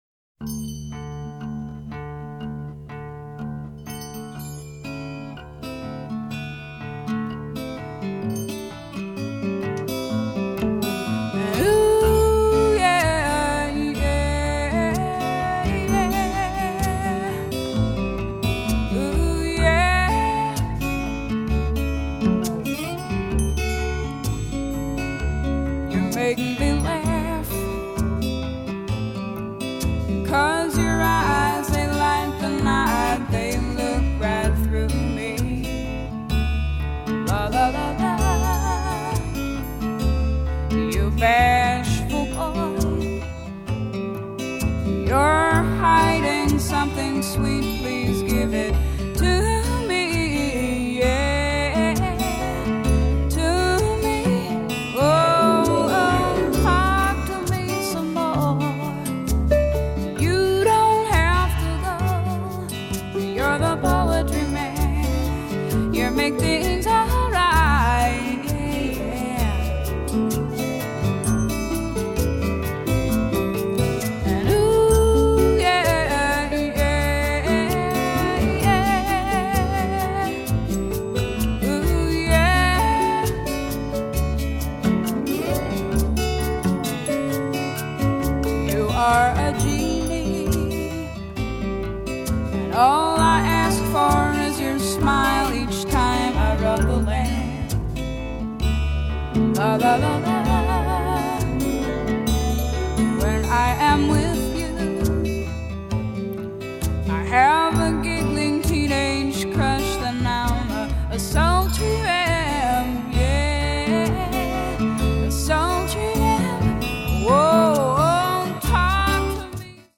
★ 以Hi-Res格式製作母帶重現巨星的委婉動人歌聲！